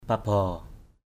/pa-bɔ:/ 1. (d.) cây lau = Saccharum arundinaceum. 2. (d.) cừu = mouton, brebis.